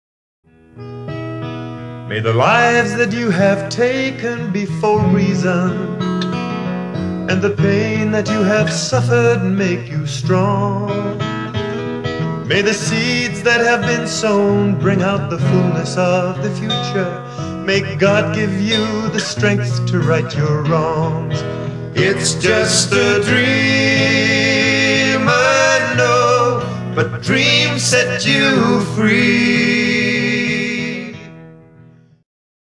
Samples are lower quality for speed.